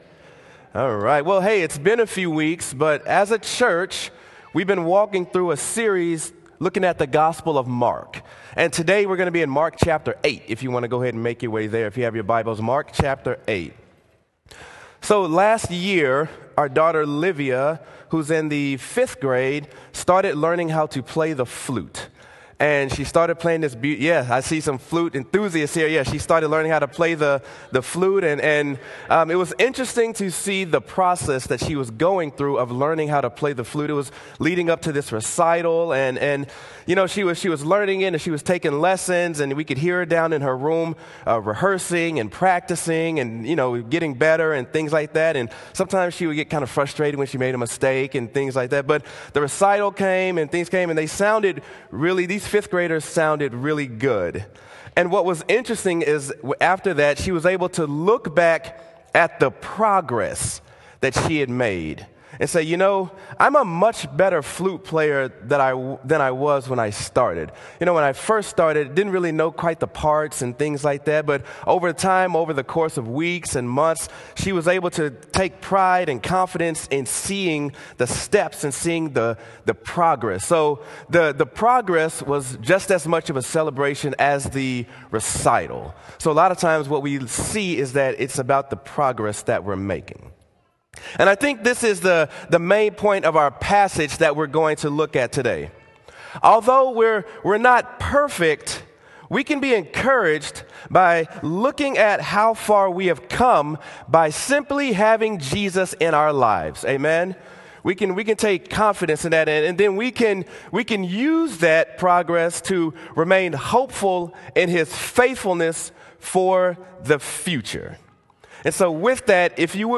Sermon: Mark: Do You See Anything?